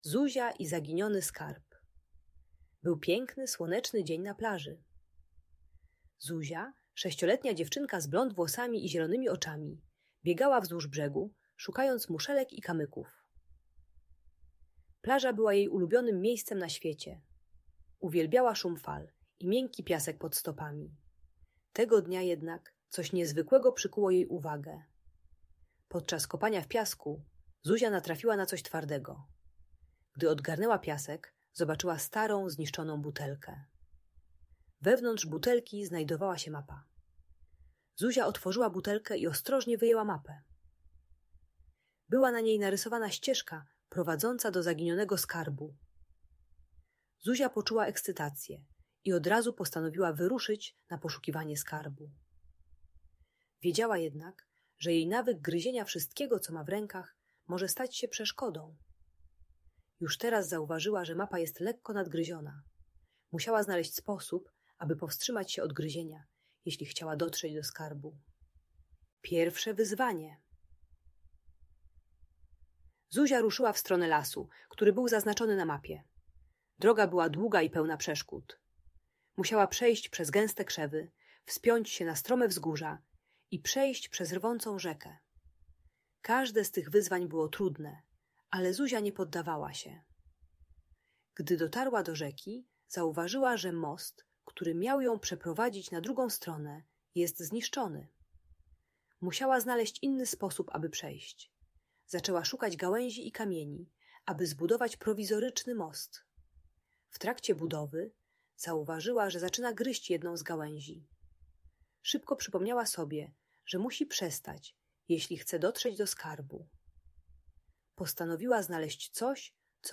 Zuzia i Zaginiony Skarb - Audiobajka